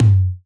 Index of dough-samples/ uzu-drumkit/ mt/